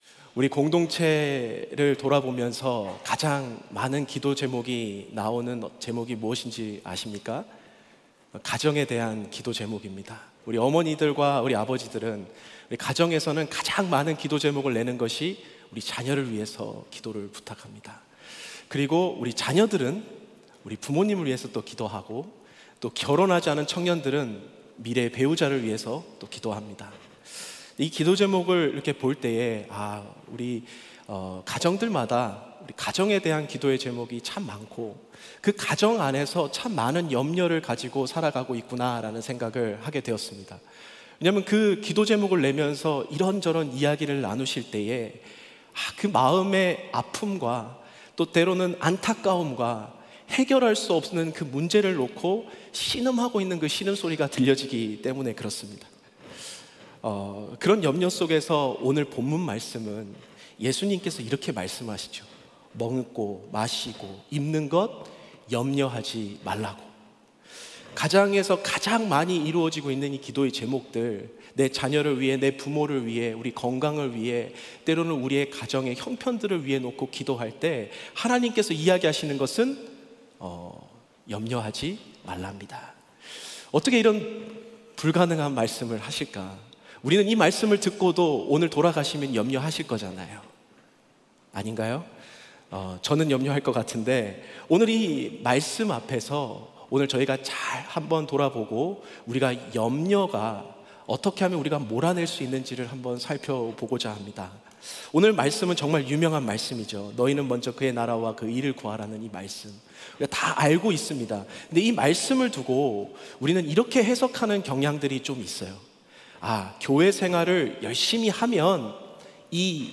예배: 토요 새벽